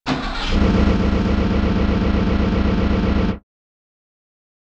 Roland.Juno.D _ Limited Edition _ GM2 SFX Kit _ 18.wav